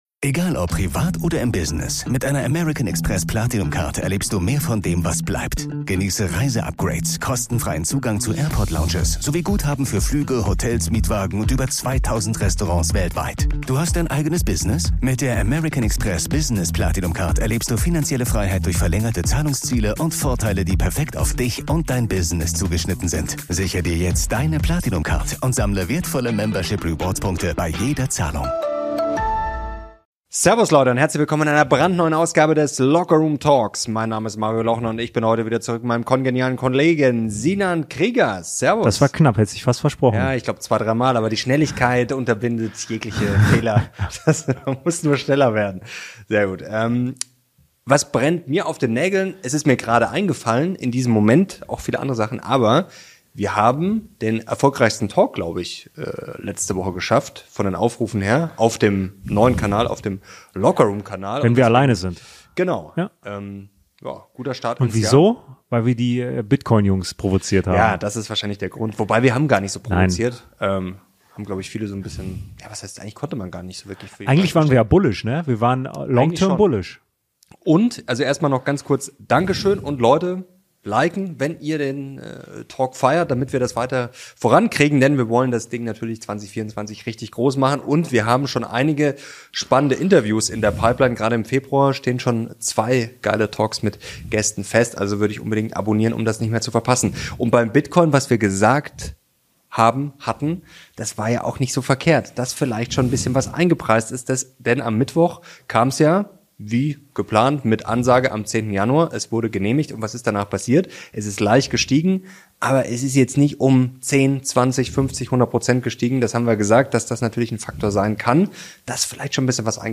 In diesem Talk sprechen wir über die Pläne zur Remigration und diskutieren über die Vorwürfe und den Masterplan, von dem wohl auch die rechte Hand von Alice Weidel, Roland Hartwig, oder Ulrich Siegmund, Fraktionsvorsitzender der AfD in Sachsen-Anhalt, erfahren haben.